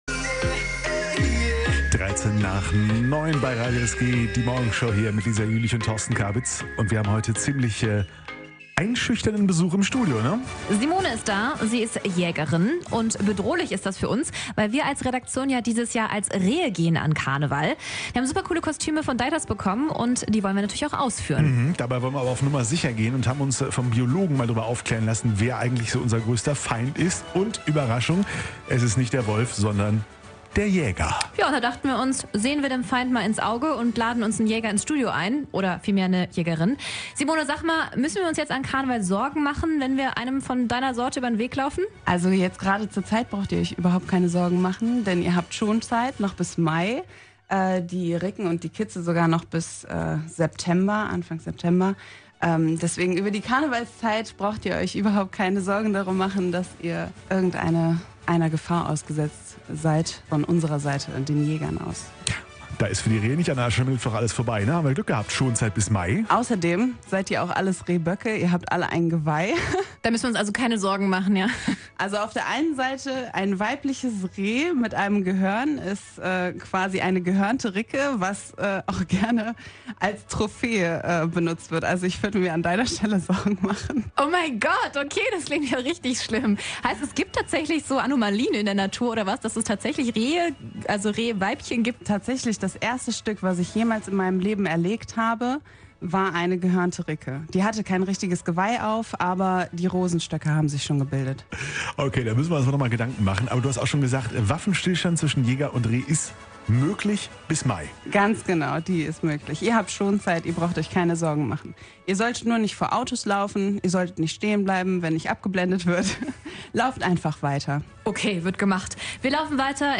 Wir haben gelernt: der größte Feind des Rehs ist nicht der Wolf, sondern der Jäger: Da haben wir uns doch prompt ne Jägerin ins Studio geholt.